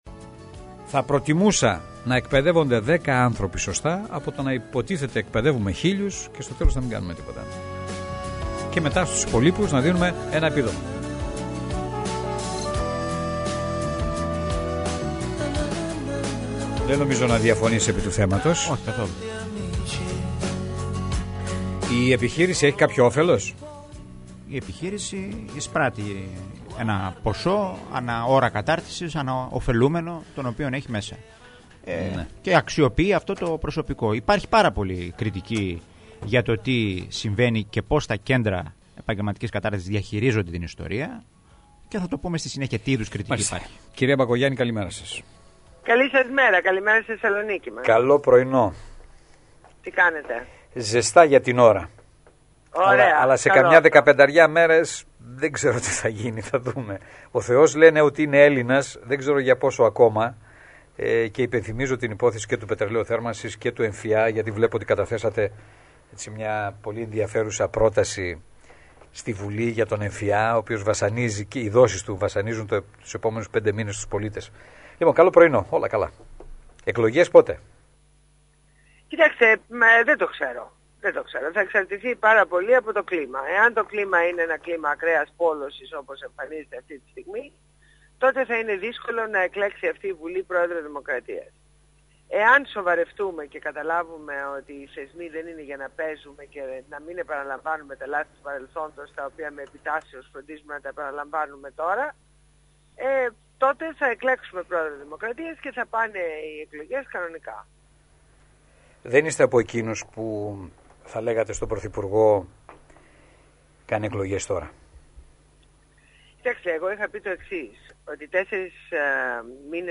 Συνέντευξη στο Ράδιο Θεσσαλονίκης